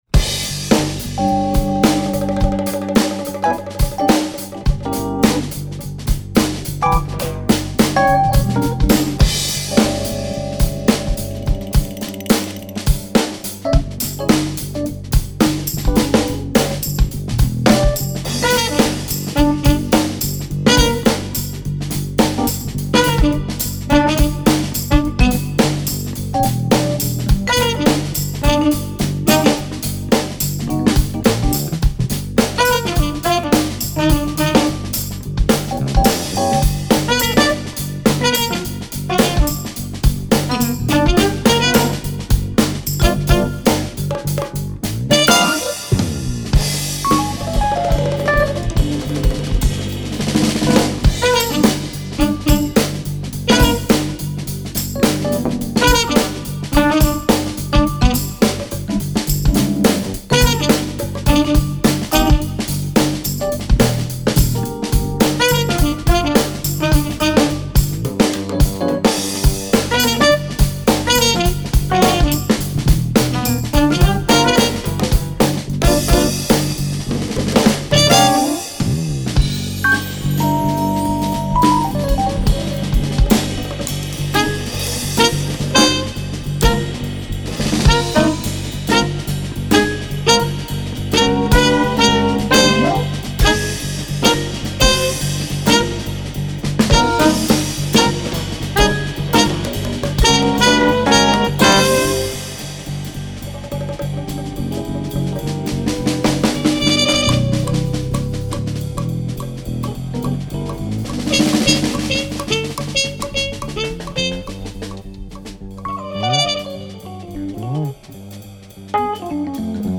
Fretless Bass, Soprano and Tenor Saxophones, Trombone sample
Percussion, Vibraphone
Electric Piano